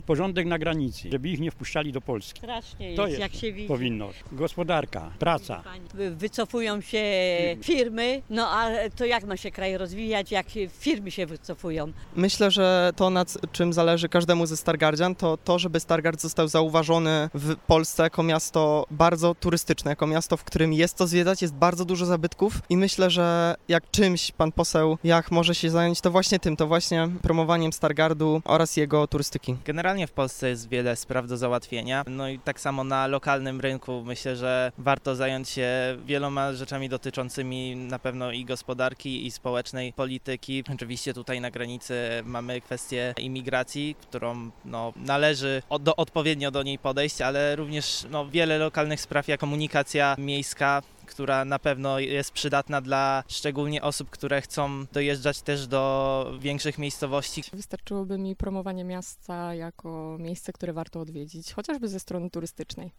Mieszkańcy Stargardu podzielili się z nami – czym ich zdaniem powinien się zająć będąc w Sejmie.